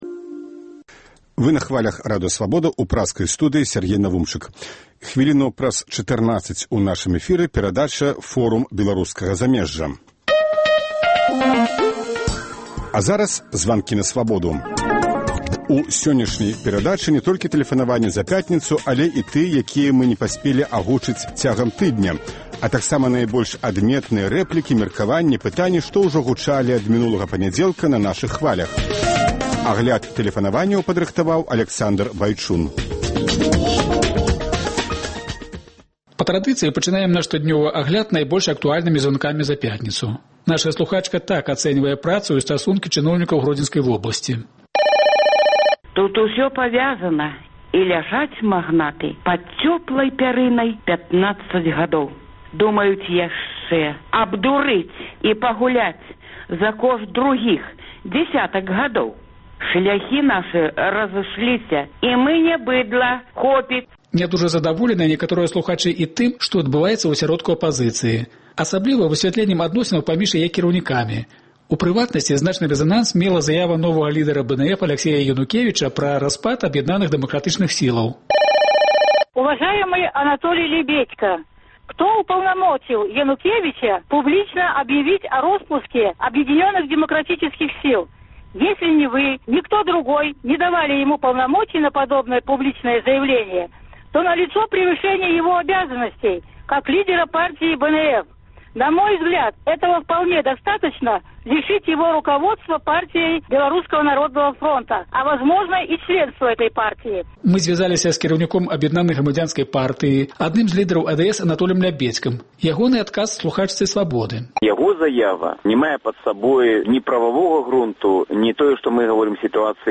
Званкі на Свабоду